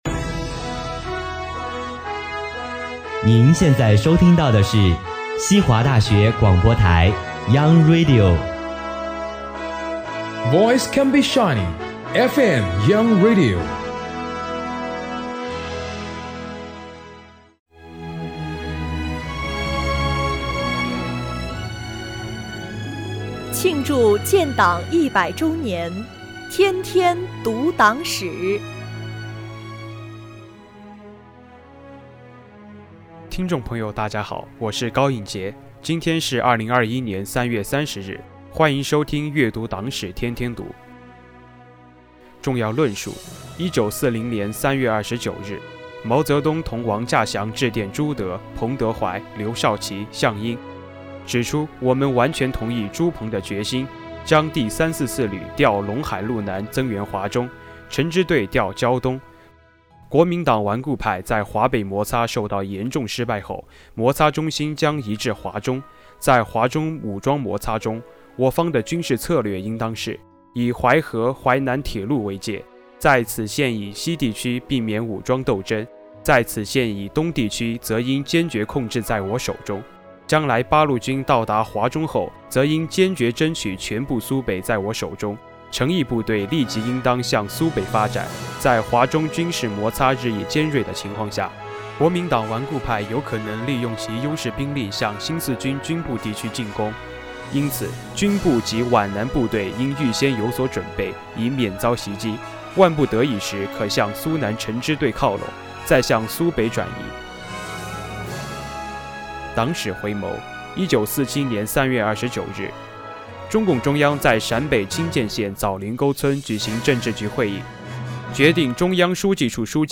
西华学子悦读党史